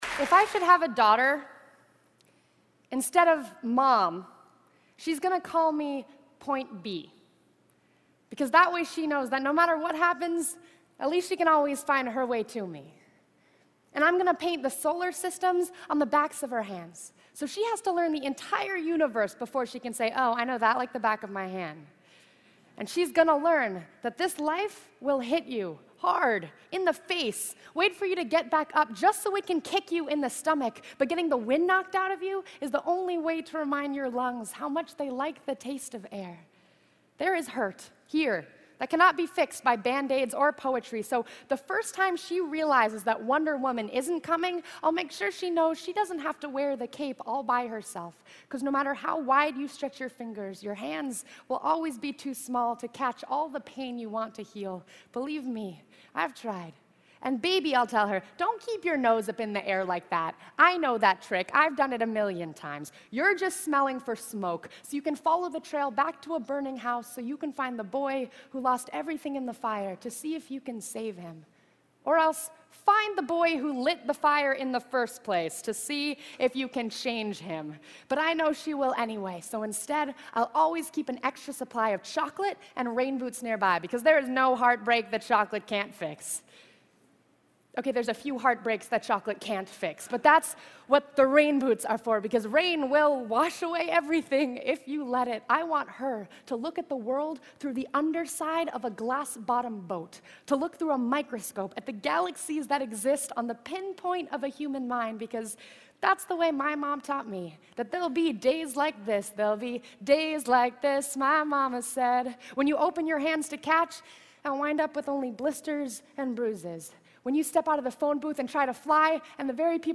TED演讲 如果我有一个女儿…(1) 听力文件下载—在线英语听力室